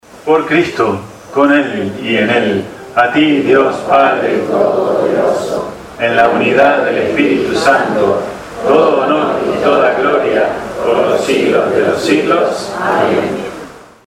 La errónea costumbre instalada hace años en esta parroquia, hace que los fieles (seguramente mal orientados en este aspecto litúrgico), reciten las palabras que sólo les corresponde escuchar (
DOXOLOGIA_FINAL.mp3